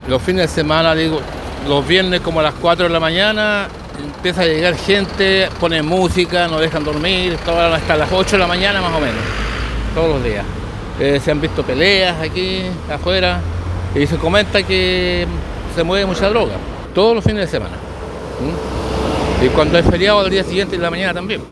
Un vecino que vive casi al frente del recinto allanado, contó a Radio Bío Bío en Valdivia que el local empezaba a funcionar cerca de las tres o cuatro de la madrugada, con música muy fuerte, molestando a los residentes.